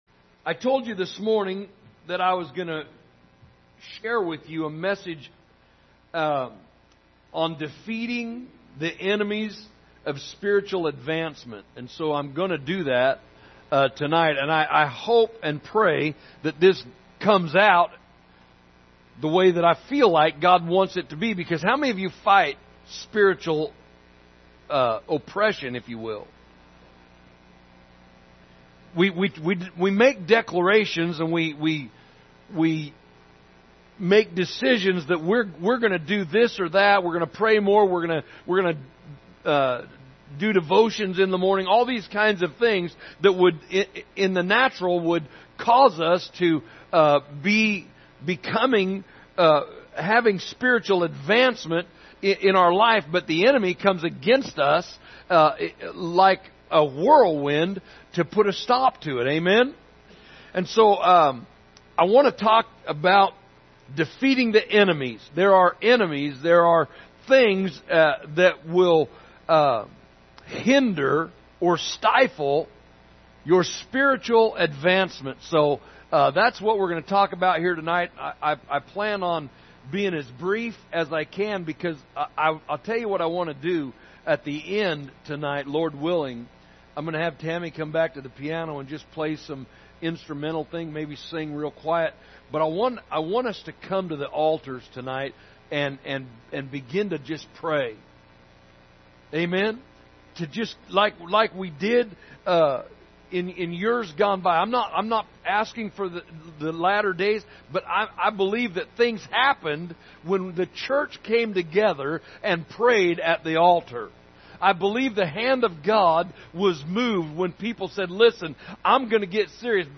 Sunday Evening Service January 9, 2022 – Defeating the Enemies of Spiritual Advancement
Recent Sermons